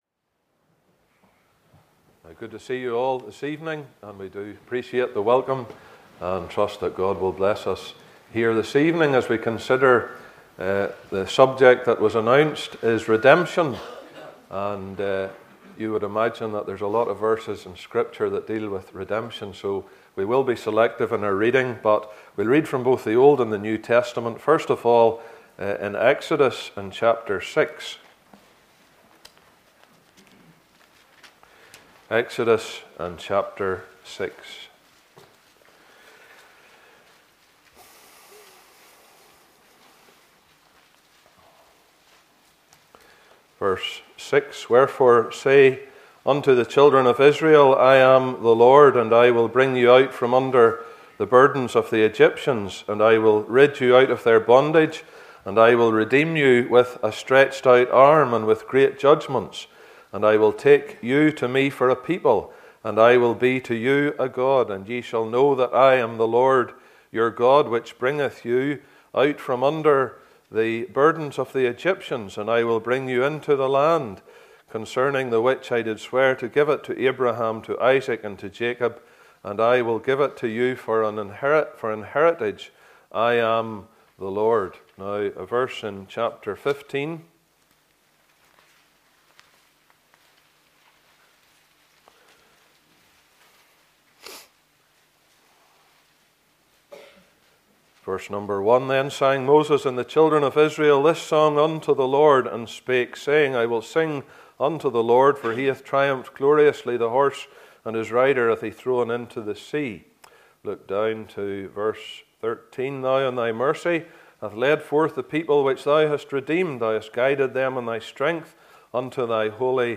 Saturday Night Ministry